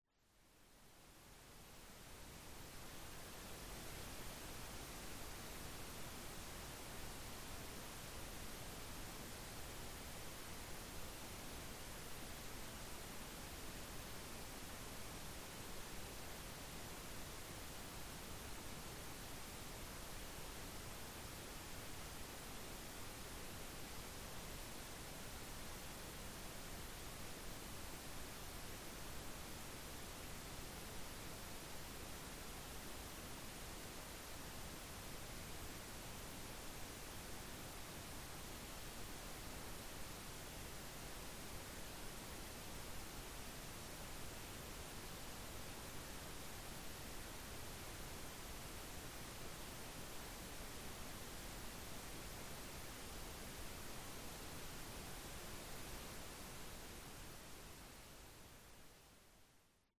Calming nature recordings and ambient soundscapes.
Rain on Window
Duration: 1:00 · Type: Nature Recording · 128kbps MP3
Rain_on_Window.mp3